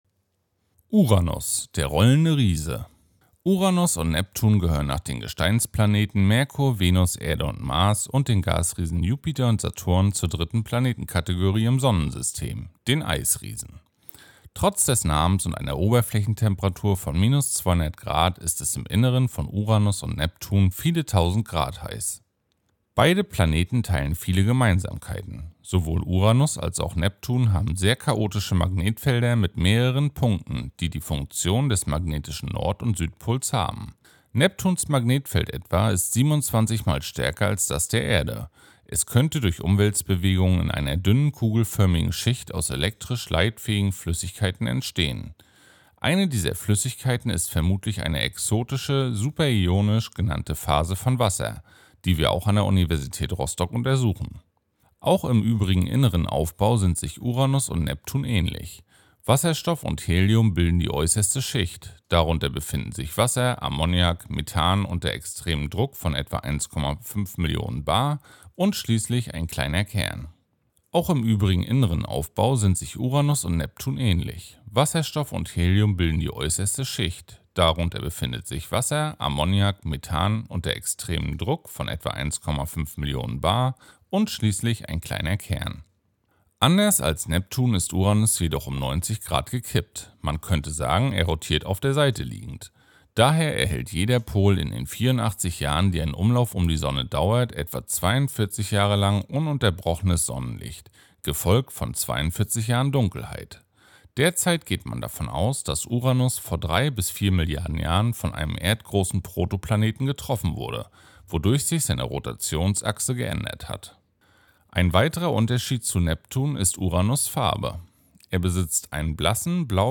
Audioguide
Promovierende vom Institut für Physik der Uni Rostock geben spannende Einblicke in ihre Forschung und erklären das Weltall.